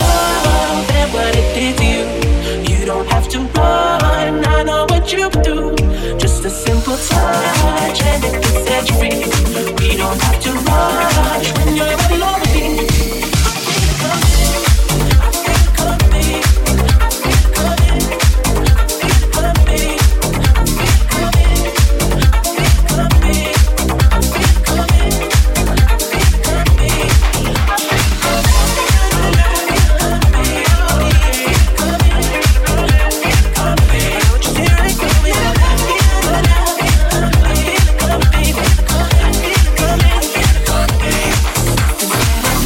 Genere: pop, club, deep, remix